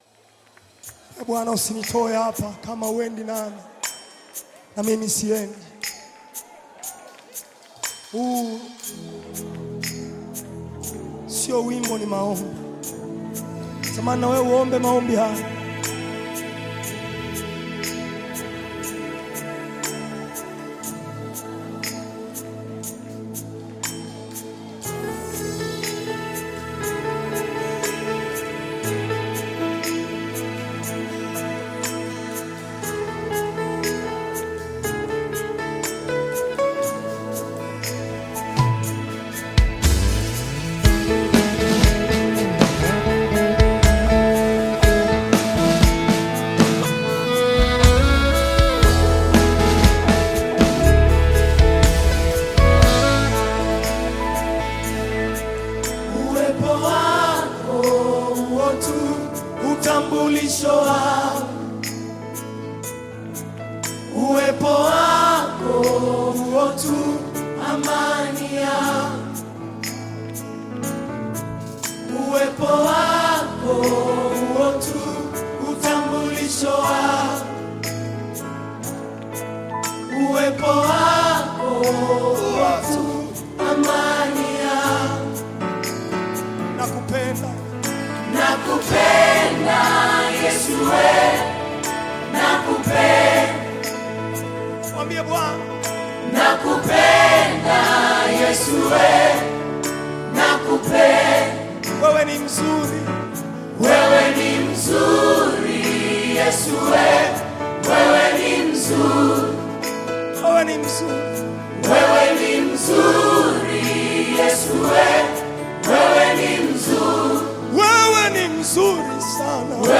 Nyimbo za Dini Worship music
Worship Gospel music track